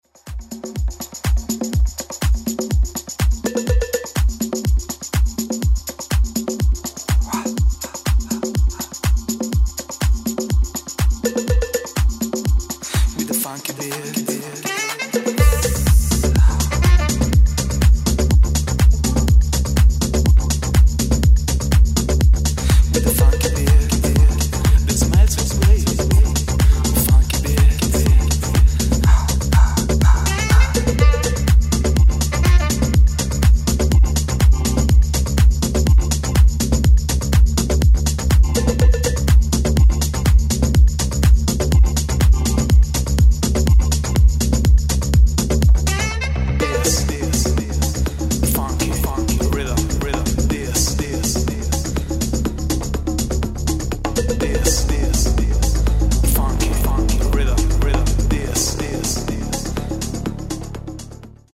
reveal an unexpected falsetto